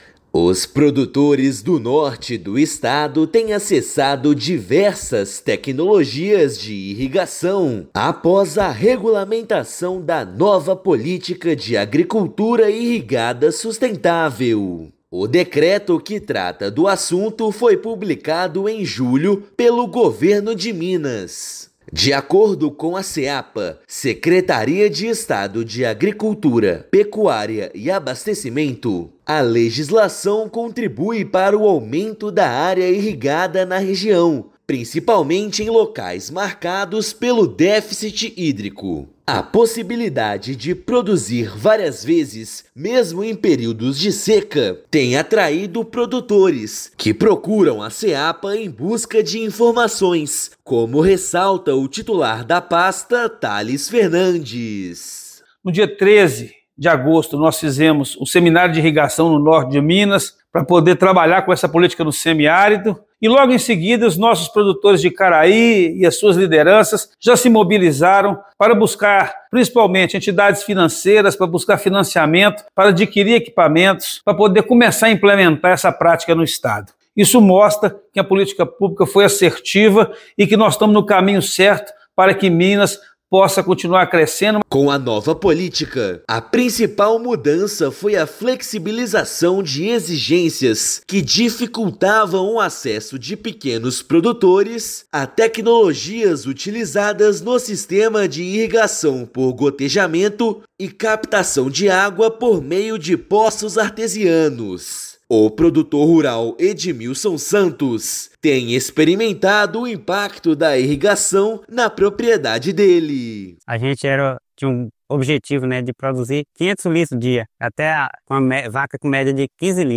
Decreto que regulamenta a Nova Política de Agricultura Irrigada Sustentável foi assinada pelo governador do Estado em julho deste ano. Ouça matéria de rádio.